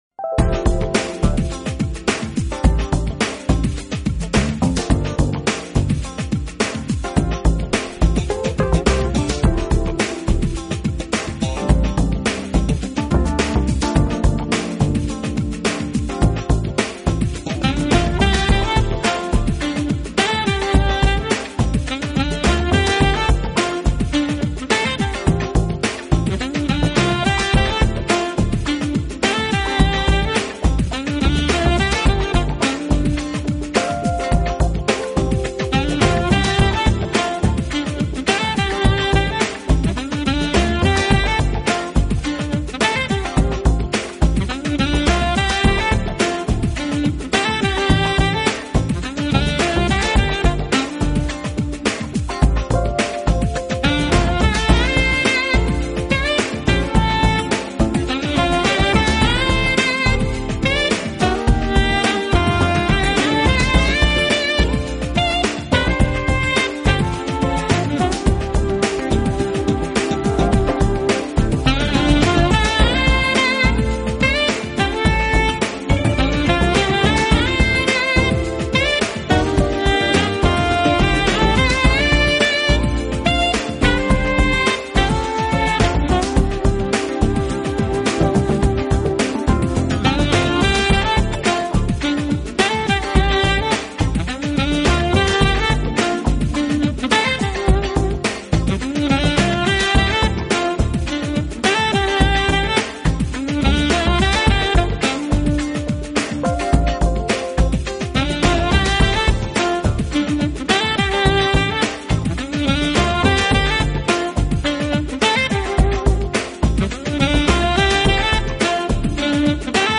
【爵士萨克斯】
音乐类型: smooth jazz